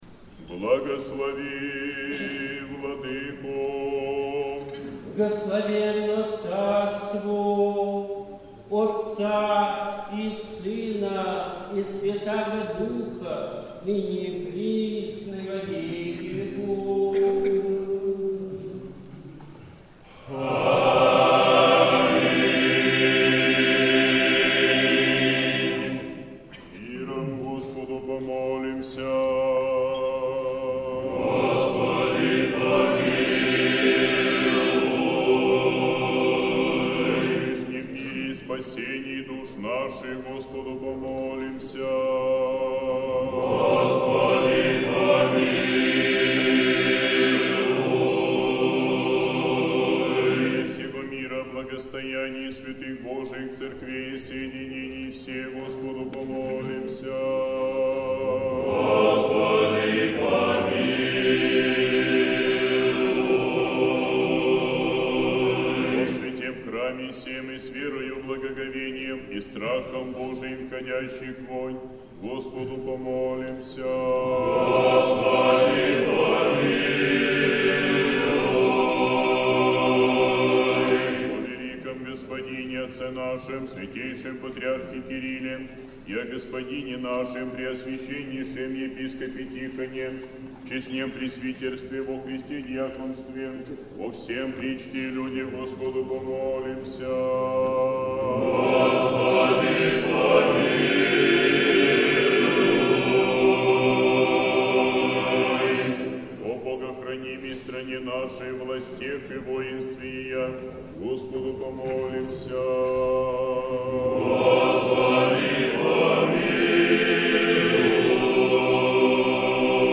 Сретенский монастырь. Божественная литургия. Хор Сретенского монастыря.
Божественная литургия в Сретенском монастыре в Неделю о блудном сыне